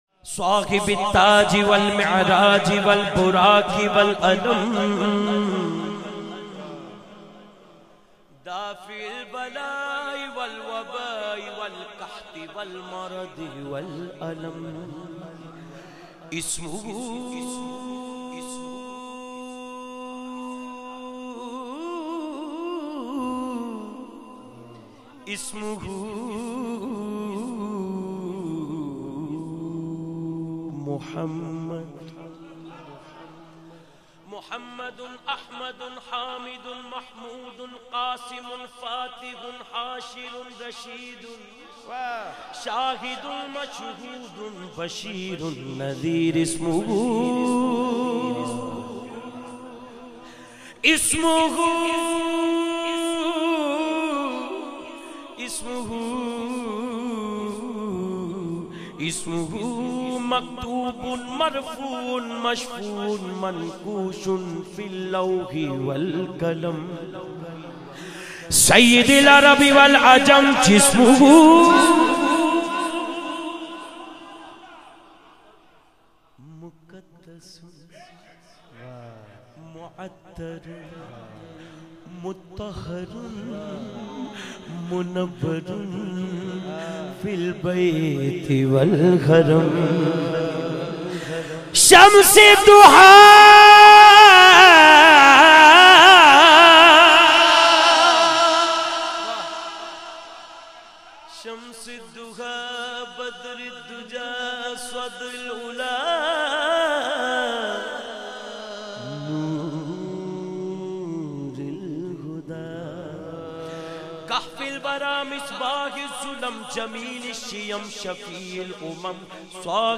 naat khawan
unique manner and melodic voice